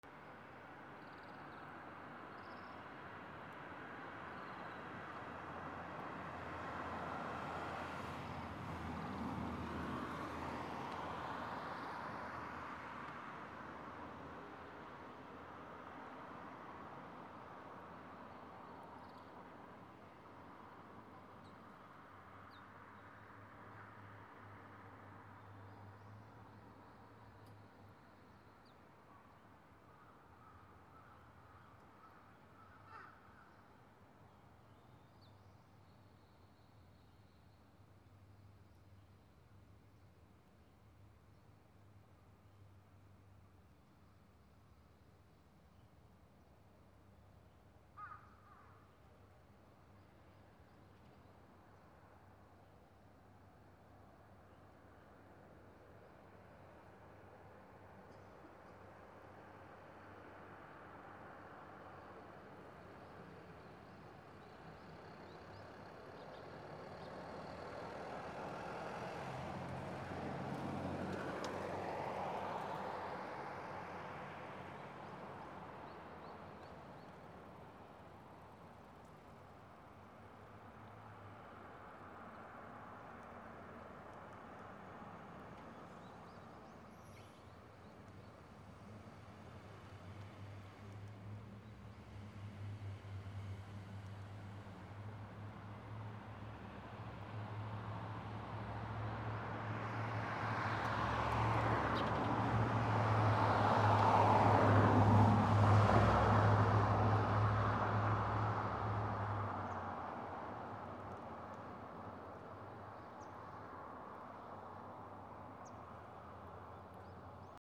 早朝 鳥
盛岡 D50